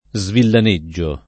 vai all'elenco alfabetico delle voci ingrandisci il carattere 100% rimpicciolisci il carattere stampa invia tramite posta elettronica codividi su Facebook svillaneggiare v.; svillaneggio [ @ villan %JJ o ], -gi — fut. svillaneggerò [ @ villane JJ er 0+ ]